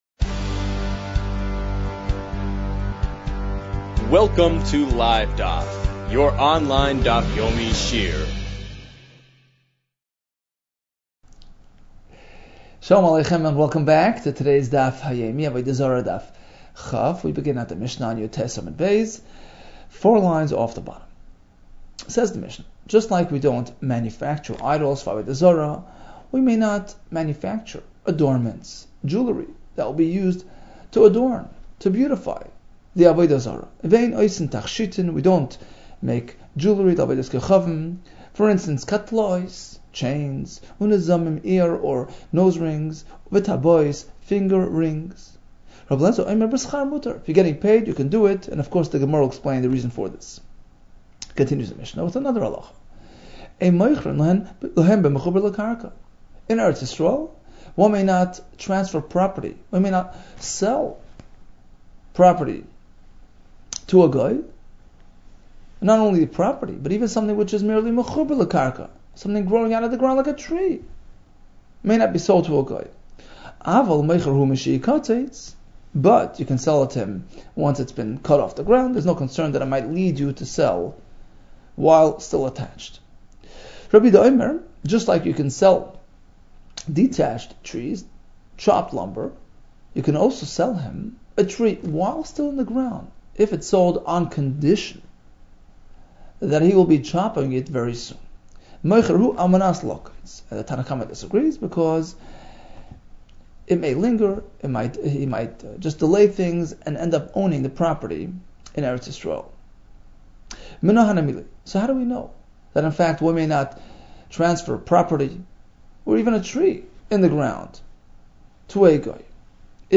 Avodah Zarah 20 - עבודה זרה כ | Daf Yomi Online Shiur | Livedaf